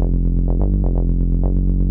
Sustained Tech House Bass Rhythm - EDM - Fm - 126.wav
Original creative-commons licensed sounds for DJ's and music producers, recorded with high quality studio microphones.
sustained_tech_house_bass_rhythm_-_edm_-_fm_-_126_7vk.ogg